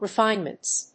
/rɪˈfaɪnmʌnts(米国英語)/